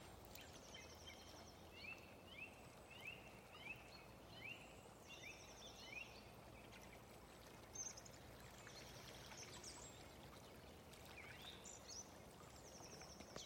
Dziedātājstrazds, Turdus philomelos
StatussUzturas ligzdošanai piemērotā biotopā (B)